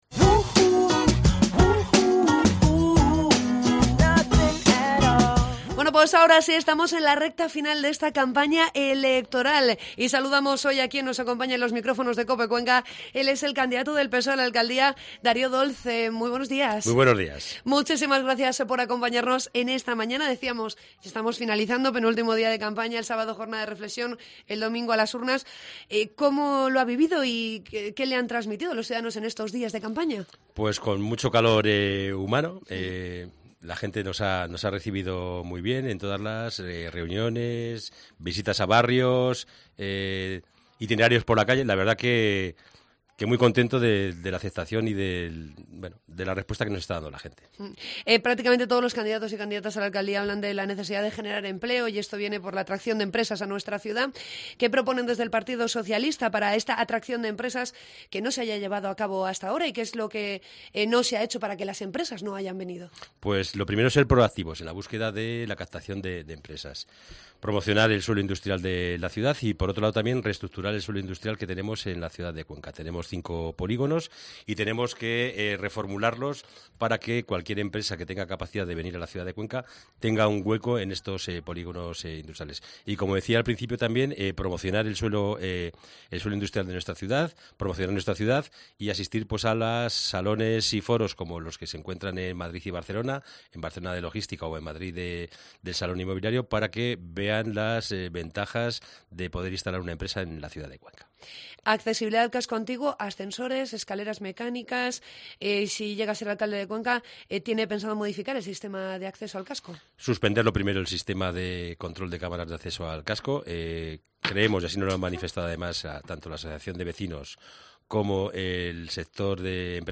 Entrevista con Darío Dolz, candidato del PSOE a la Alcaldía de Cuenca